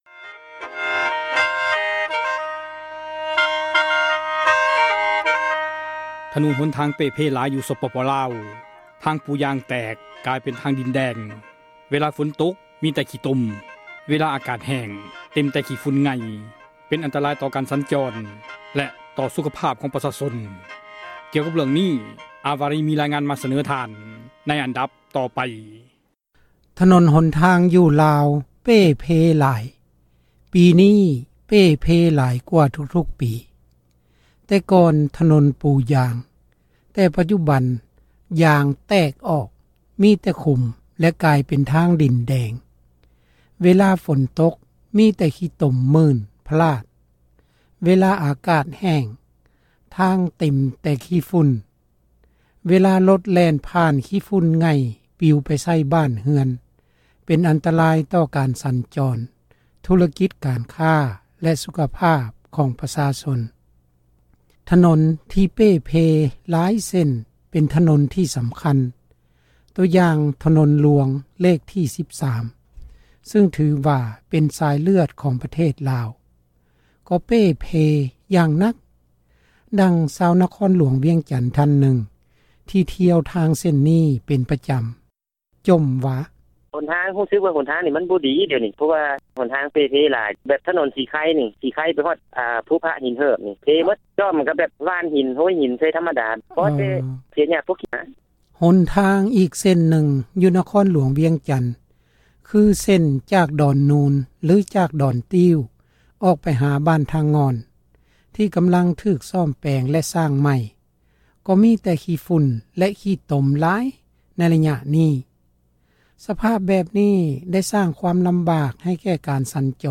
ຖນົນ ທີ່ ເປ່ເພ ຫລາຍ ເສັ້ນ ເປັນຖນົນ ທີ່ ສຳຄັນ ຕົວຢ່າງ ຖນົນ ຫລວງ ເລກ ທີ 13 ຊຶ່ງ ຖືວ່າ ເປັນ ສາຍເລືອດ ຂອງ ປະເທດ ລາວ ກໍ ເປ່ເພ ຢ່າງ ໜັກ. ດັ່ງ ຊາວ ນະຄອນ ຫລວງ ວຽງຈັນ ທ່ານນຶ່ງ ທີ່ ທຽວທາງ ເສັ້ນນີ້ ເປັນ ປະຈຳ ຈົ່ມວ່າ:
ກ່ຽວກັບ ເຣື່ອງ ນີ້ ຂ້າພະເຈົ້າ ໄດ້ ສຳພາດ ຄົນ ຂັບຣົດ ຂົນໄມ້ ຜູ້ນຶ່ງ ຈາກ ແຂວງ ຄຳມ່ວນ ອອກໄປ ວຽດນາມ ເປັນ ປະຈຳ ວ່າ: